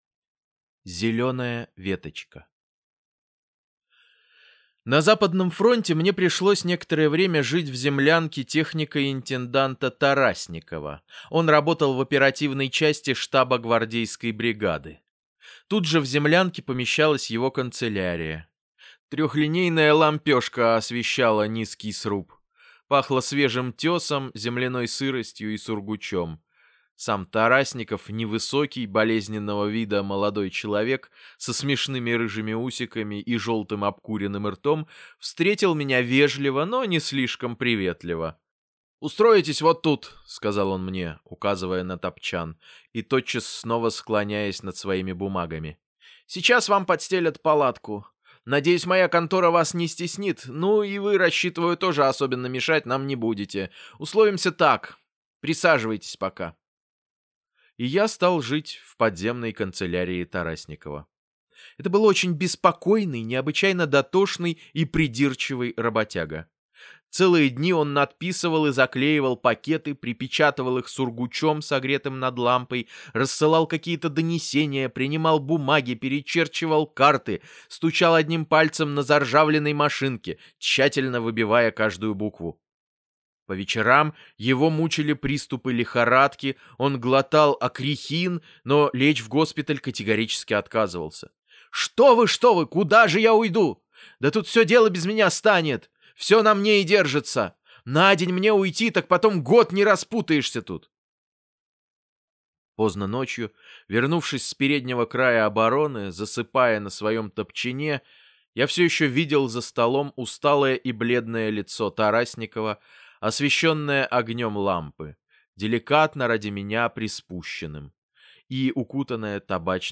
Зеленая веточка - аудио рассказ Кассиля - слушать онлайн